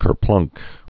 (kər-plŭnk)